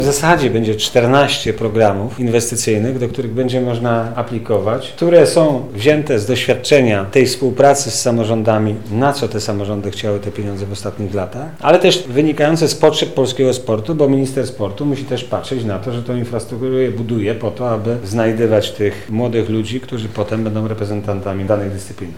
W piątek (10 października) w Lubelskim Urzędzie Wojewódzkim odbyła się konferencja, pt. „Programy infrastrukturalne w Ministerstwie Sportu i Turystyki”, na której przedstawione zostały możliwe formy wsparcia ze strony Ministerstwa dla lokalnego sportu.
– mówi Ireneusz Raś, Sekretarz Stanu w Ministerstwie Sportu i Turystyki.